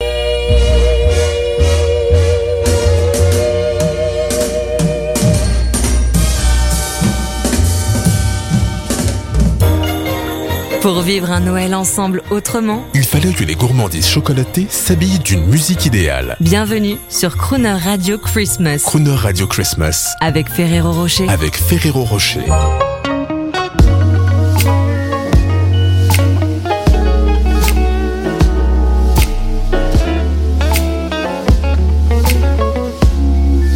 4 exemples de messages Ferrero pour Crooner Christmas, en contexte :
Crooner_Ferrero_Rocher-.mp3